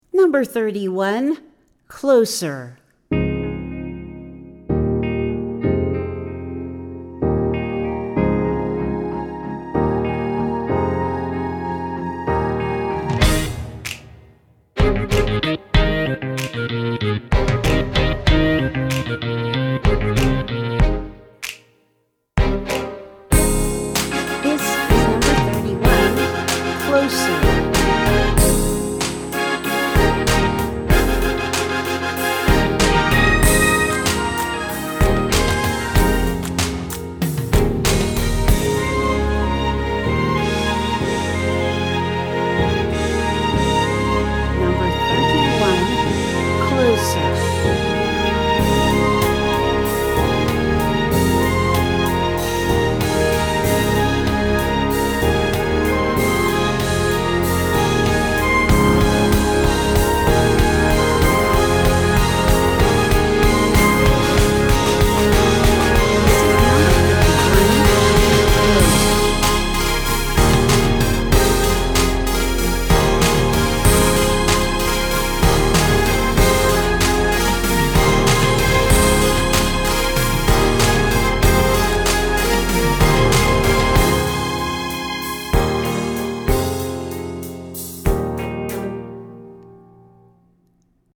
1:25 Style: Orchestration Category